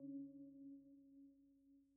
sonarTailAirMedium2.ogg